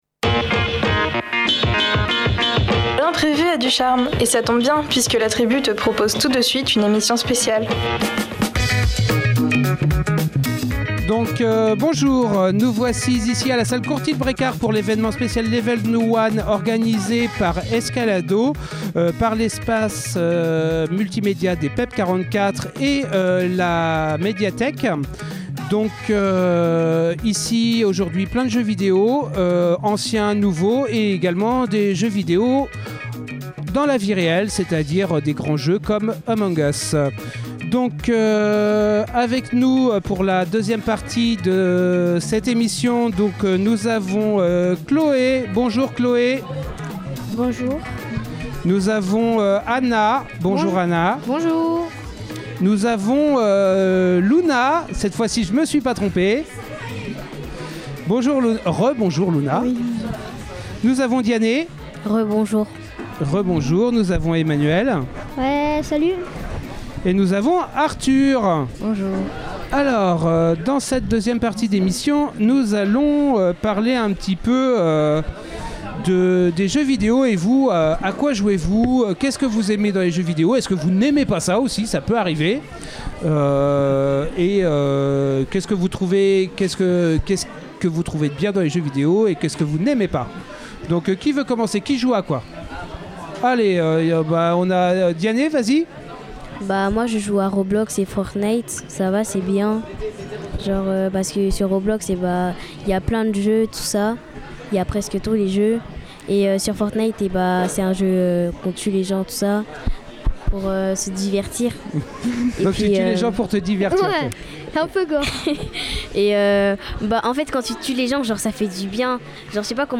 La Tribu etait présente lors de l’évènement LEVEL ONE à la salle du Courtil Brécard autour du jeu vidéo, organisé par l’association Escalado.